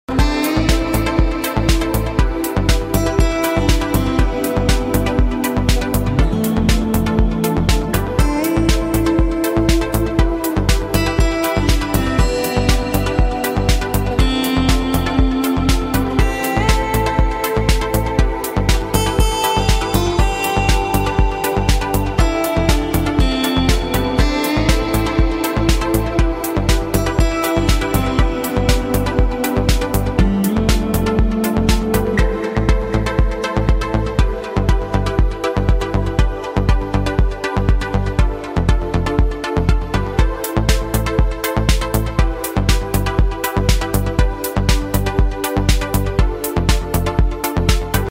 Add Effects to our virtual guitars
Live Looping Crafting a Solo with Ample Guitar Martin